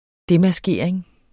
Udtale [ ˈdemaˌsgeˀɐ̯eŋ ]